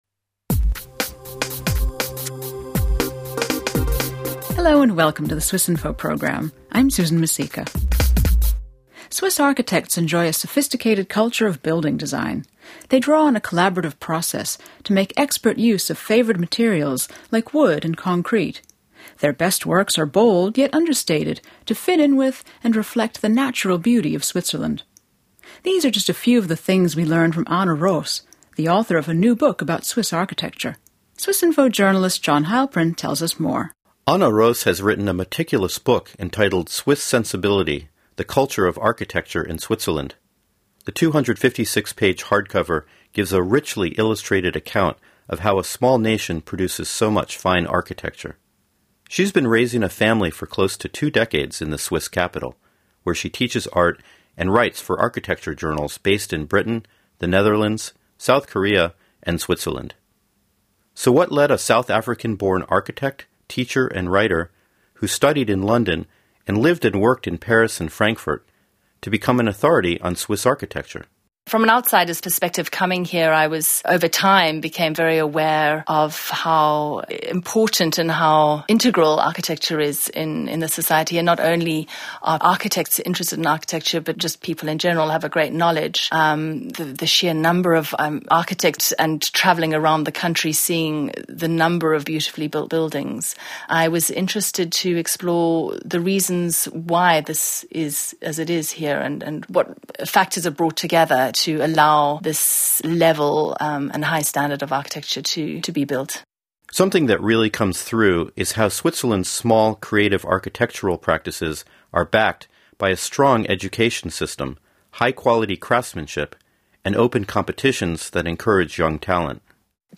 A talk with architect